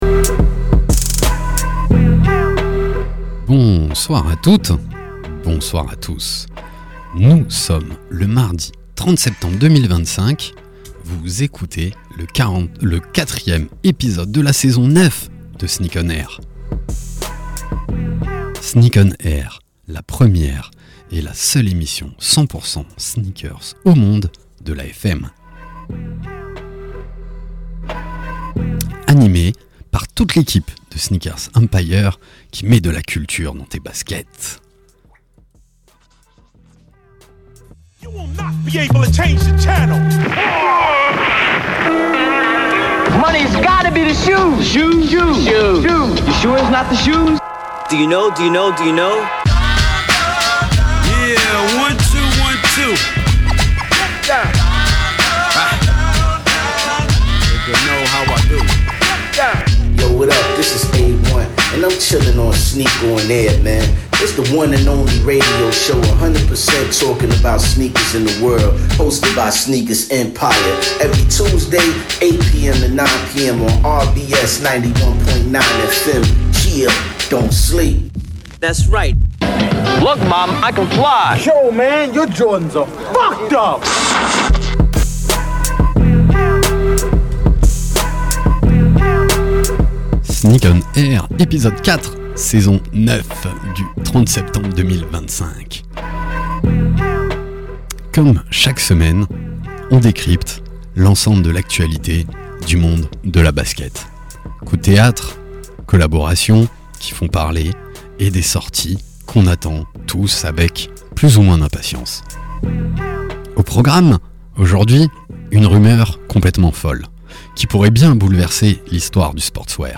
Sneak ON AIR, la première et la seule émission de radio 100% sneakers au monde !!! sur la radio RBS tous les mardis de 20h à 21h.
Pour cet épisode, nous vous proposons une heure de talk, d’actus, et de débats autour des faits marquants de l’univers de la sneaker avec tous nos chroniqueurs.